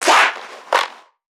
NPC_Creatures_Vocalisations_Infected [112].wav